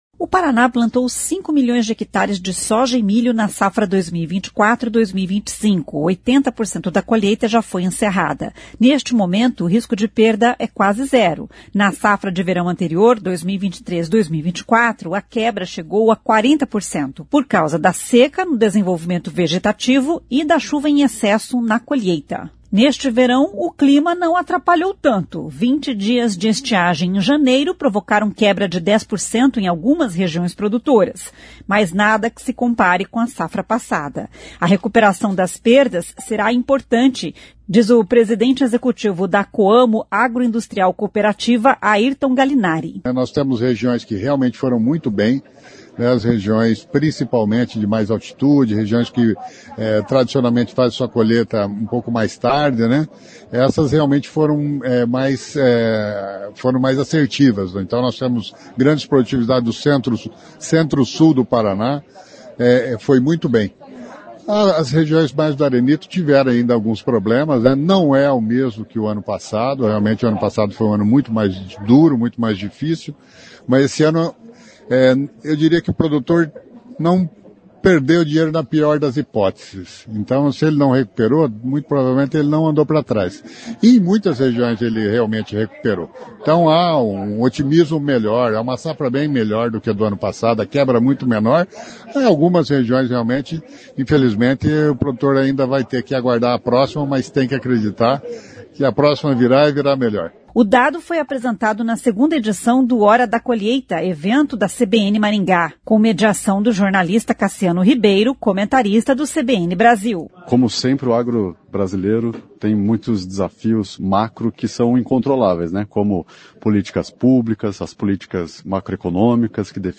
[ouça o áudio] O dado foi apresentado na 2ª edição do Hora da Colheita, evento da CBN Maringá.